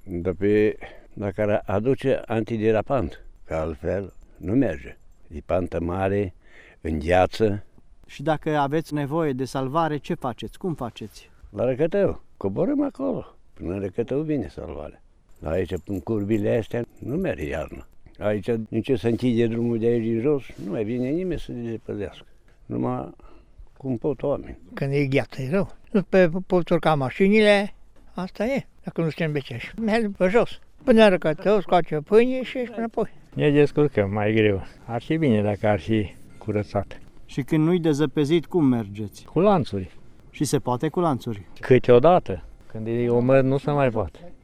Oamenii care stau în această zonă povestesc că salvarea vine până în localitatea Răcătău, iar bolnavii trebuie să meargă câțiva kilometri pe jos, ca să ajungă la ea:
oameni-din-catune.wav